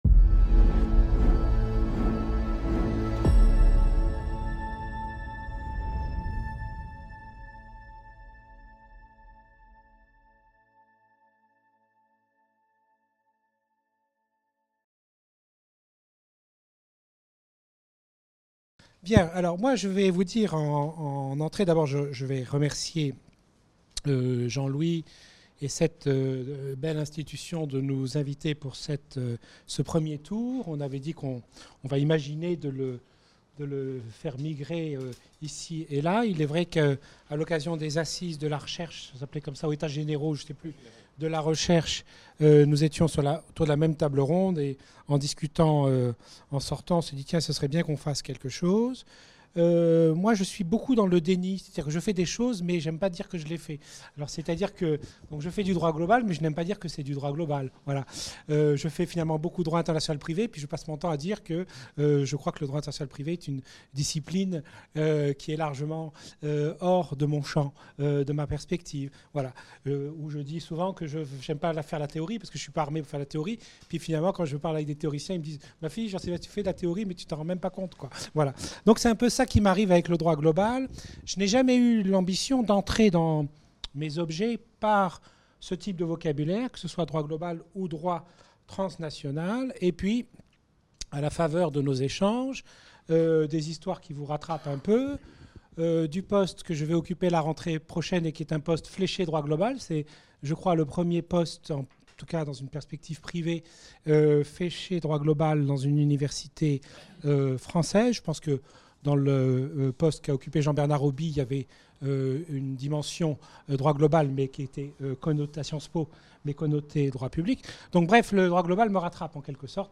Cours/Séminaire